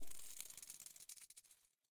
Minecraft Version Minecraft Version snapshot Latest Release | Latest Snapshot snapshot / assets / minecraft / sounds / block / creaking_heart / hurt / trail7.ogg Compare With Compare With Latest Release | Latest Snapshot